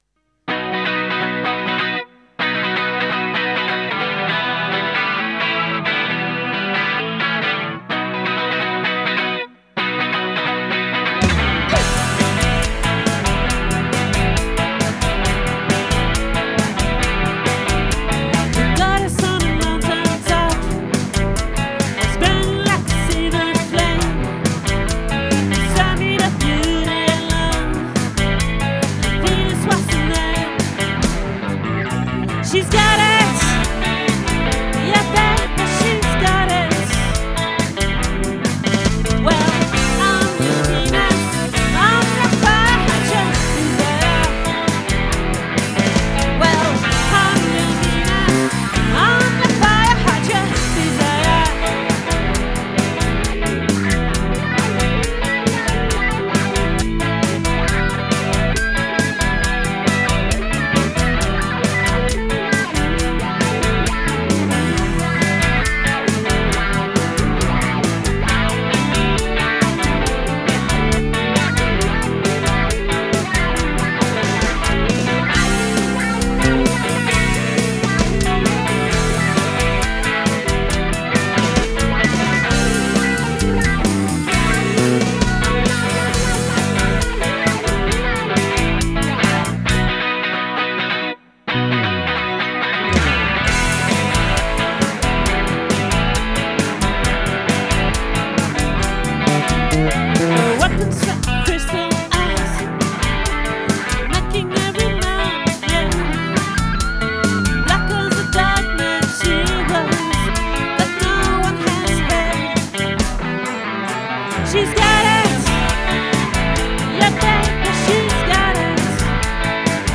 🏠 Accueil Repetitions Records_2025_03_03